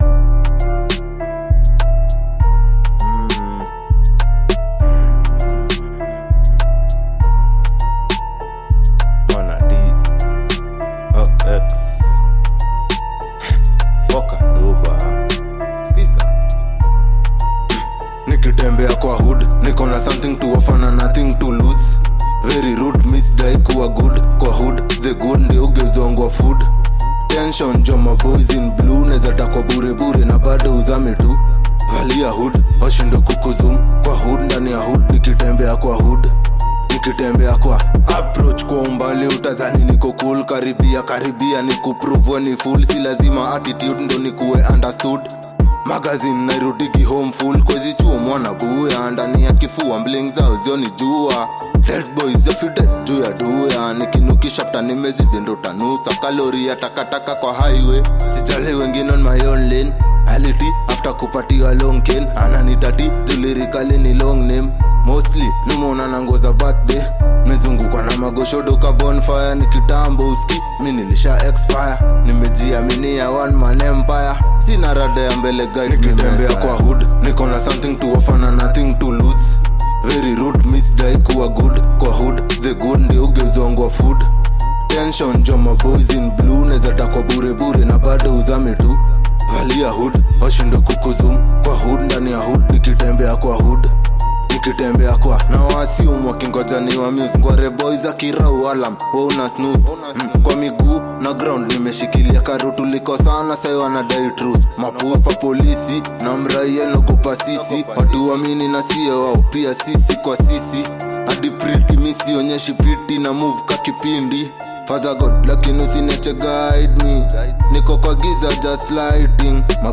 With its gritty vibe and strong storytelling